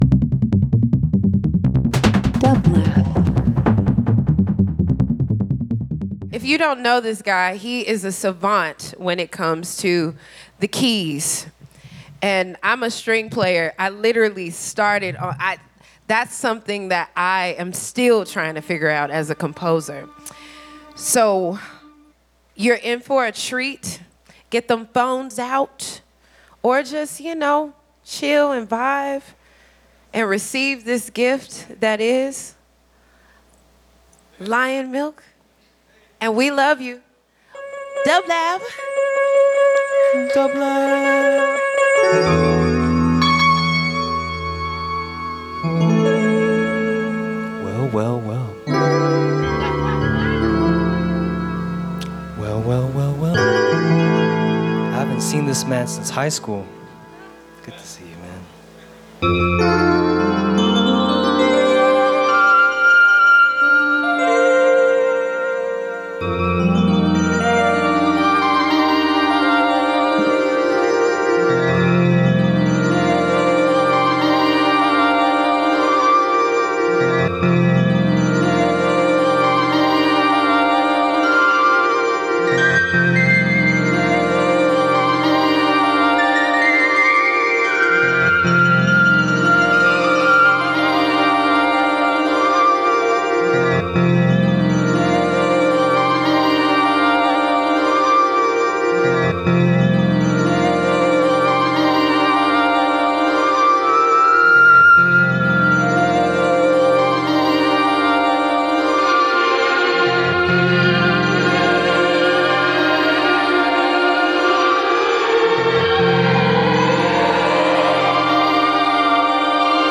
LIVE FROM THE LOFT @ PORTER STREET STUDIO – OCT 11, 2025
Ambient Dance Electronic Funk/Soul Live Performance Synth